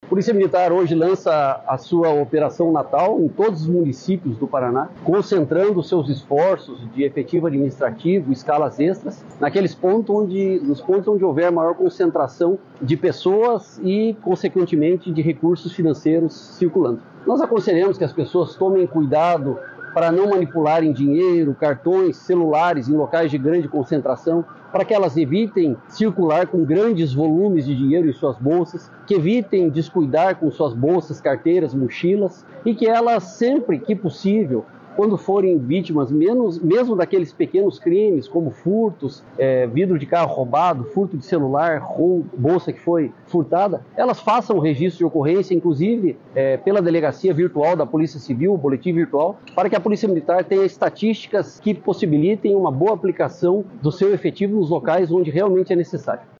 Sonora do chefe do Estado-Maior da PM e subcomandante-geral em exercício, coronel Valmor Anderson Pereira, sobre a Operação Natal 2024